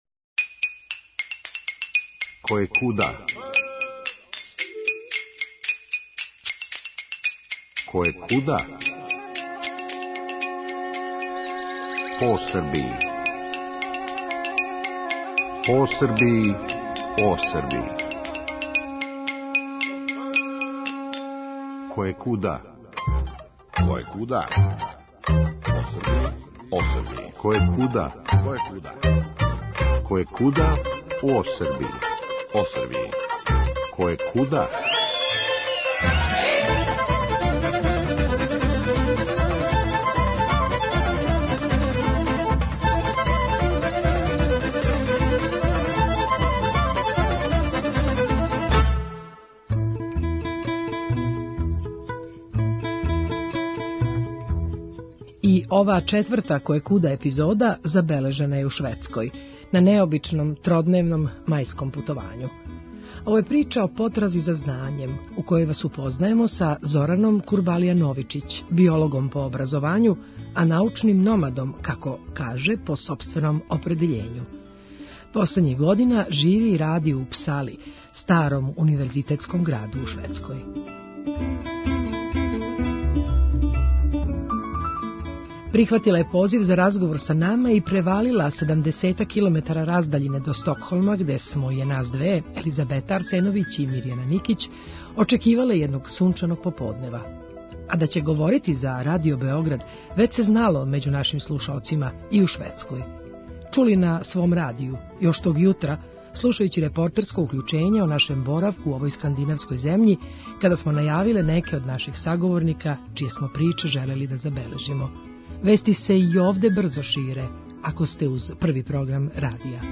Емисија Радио Београда 1